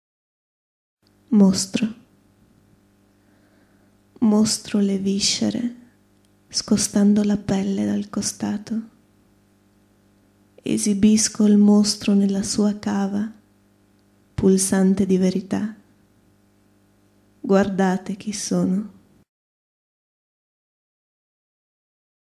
Poesie recitate da artisti